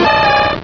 Cri de Machopeur dans Pokémon Rubis et Saphir.